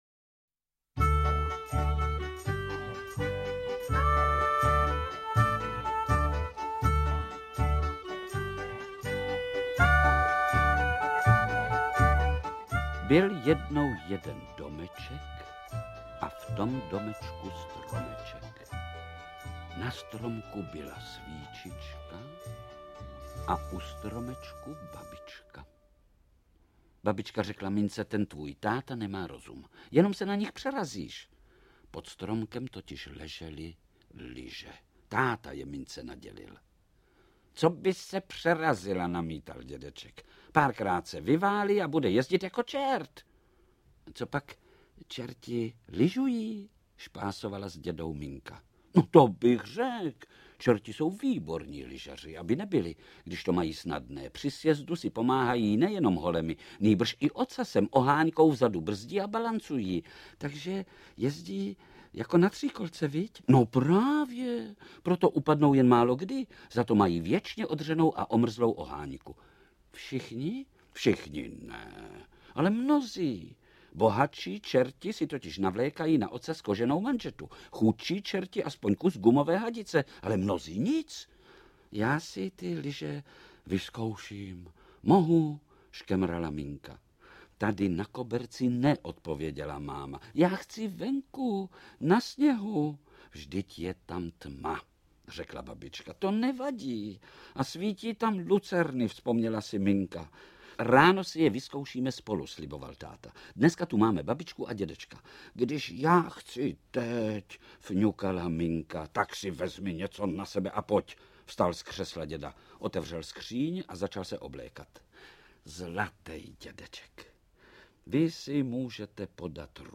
• AudioKniha ke stažení Pět báječných strýčků
Interpreti:  Josef Dvořák, František Filipovský, Rudolf Hrušínský, Jiří Lábus, Václav Postránecký
Pohádka Františka Nepila v podání slavných českých herců.